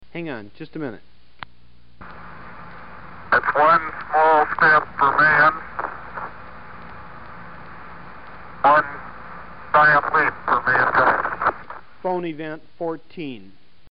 Neil Armstrong's message from the surface of the moon, time shifted and delivered by phone makes an irresistable Phoneyvent.